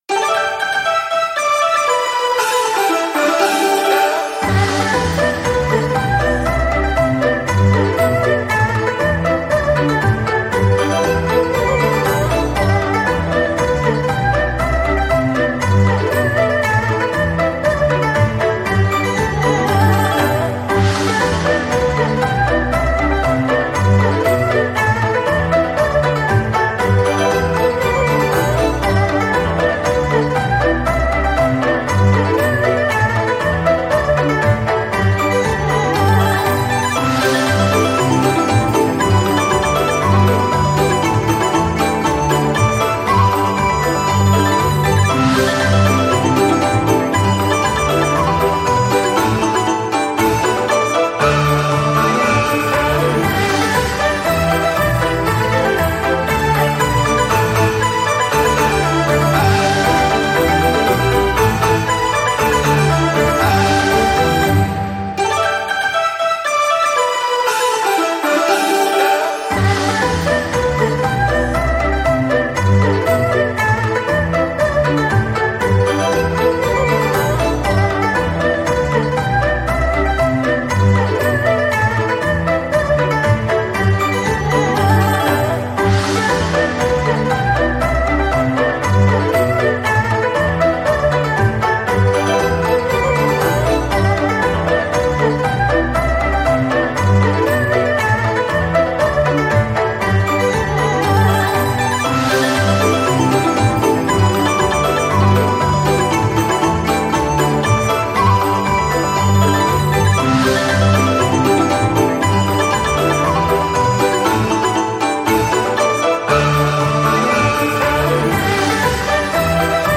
• musica de fondo oriental para videos y juegos alegres
Folk, Música del mundo
Música de fondo oriental para juegos alegres